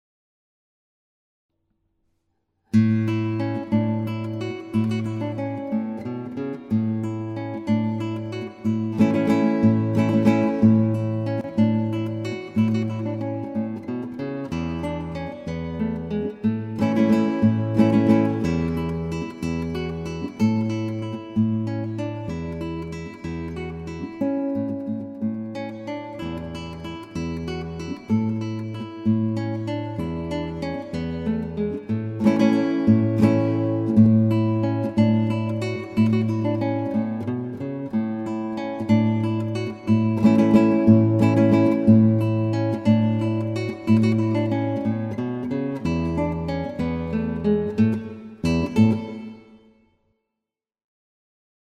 Solo gitaar
• Thema: Spaans